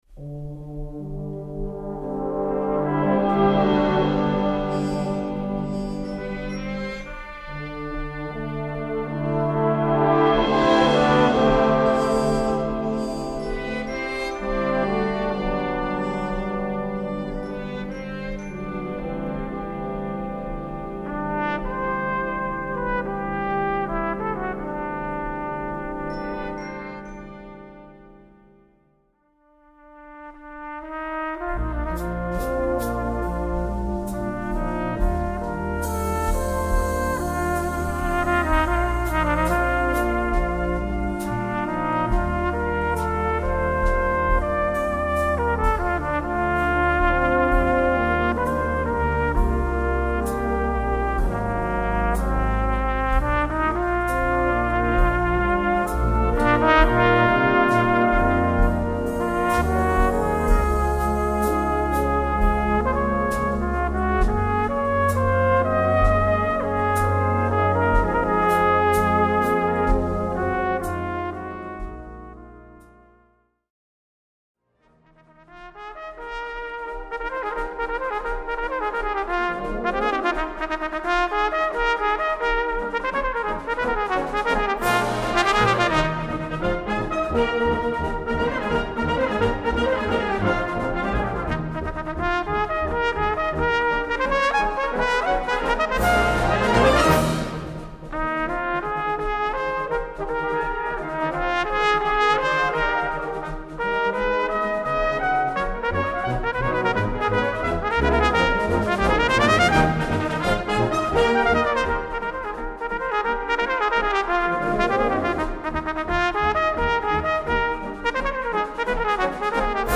Cornet et Brass Band